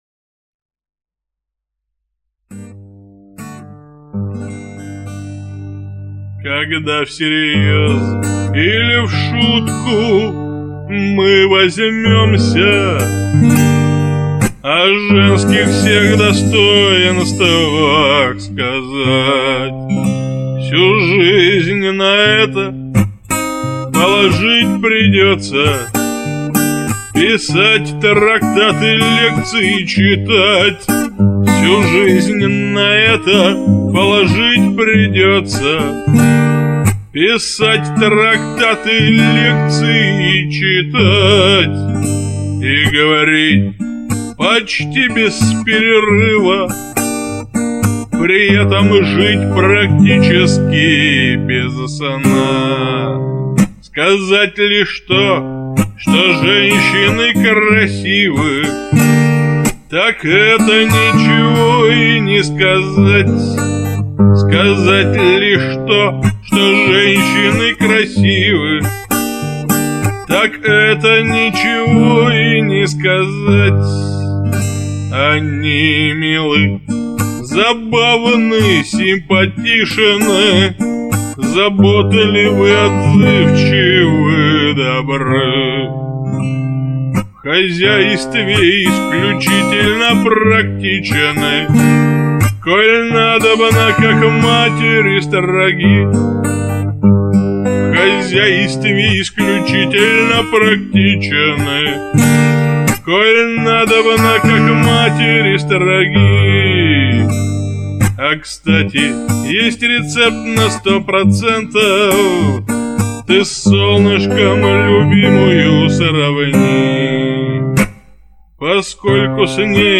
• Жанр: Шансон